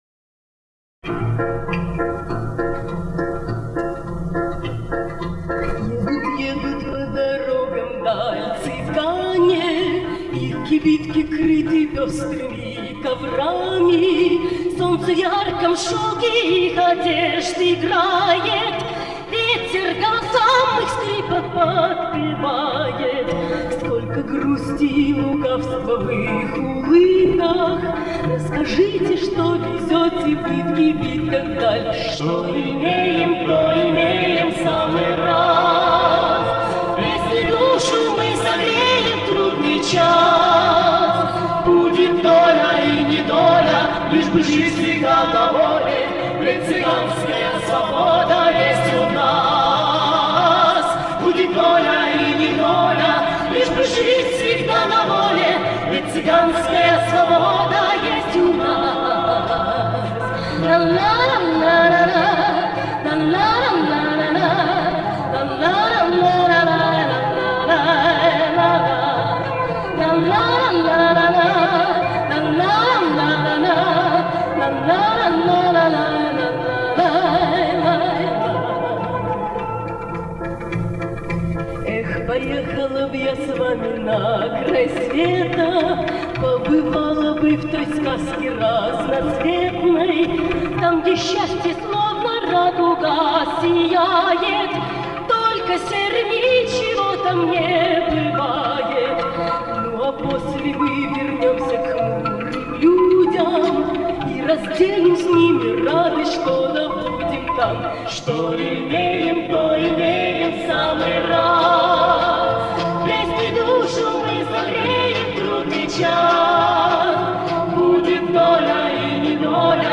Еще несколько реставраций студийных и живых записей